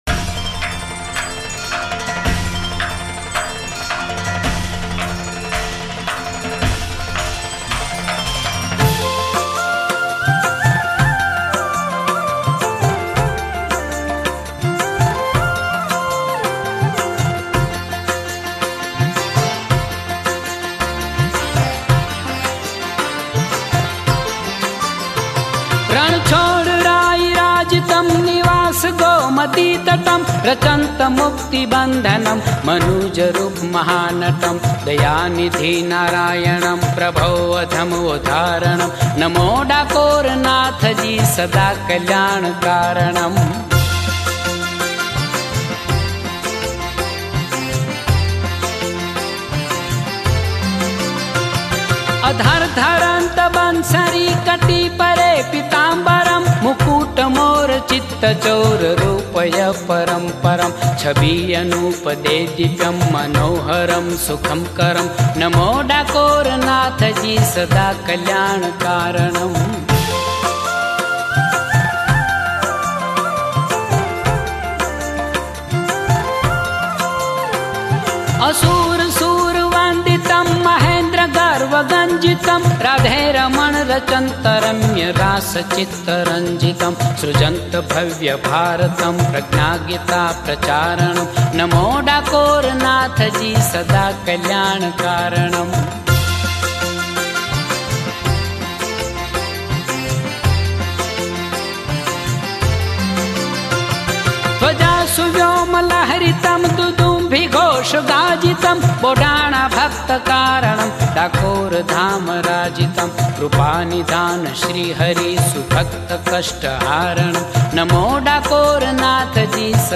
Bhakti Mantra Jaap